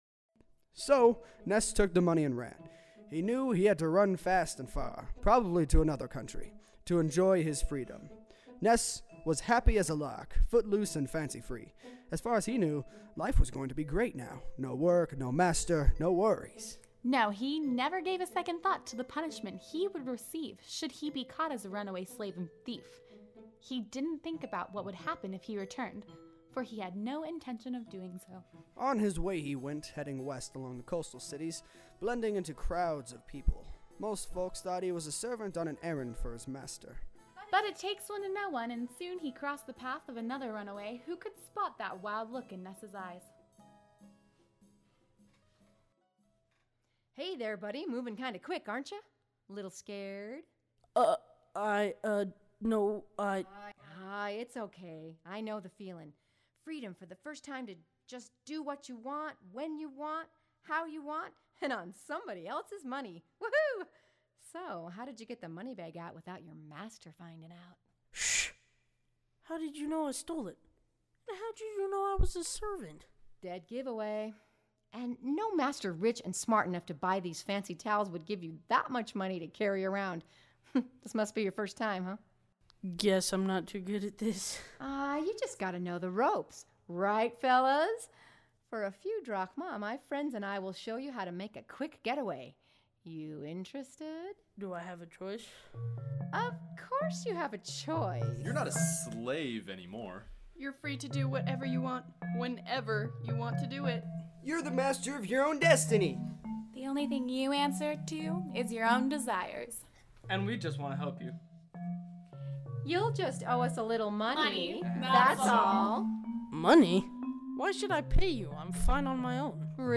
Sneak Preview –  Listen to this sample of the Audio Performances you will get with the Helpful Production Bundle for What a Fine Mess, Ness!
Ness-Audio-Perf.-Sample-Scene-4.mp3